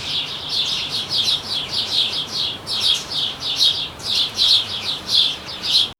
Gentle morning breeze, chirping sparrows, soft basket rustle.
gentle-morning-breeze-chi-o2jdf5ne.wav